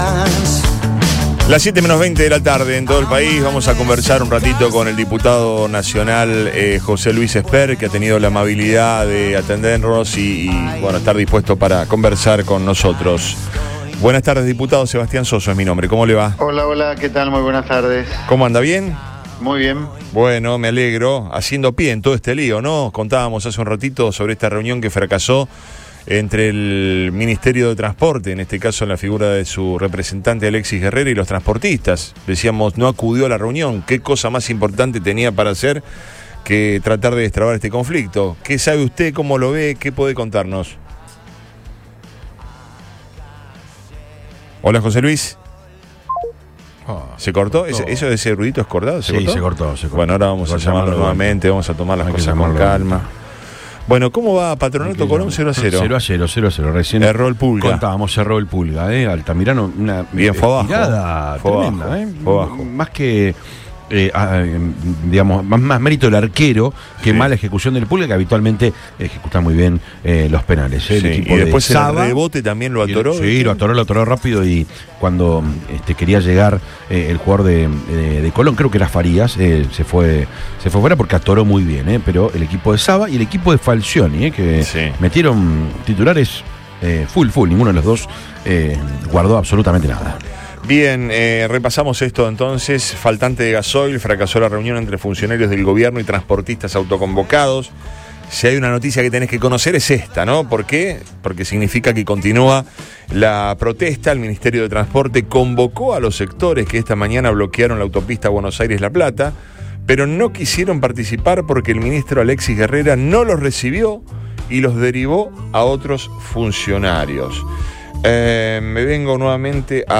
José Luis Espert, economista y diputado nacional por “Avanza Libertad”, dialogó con Radio Boing.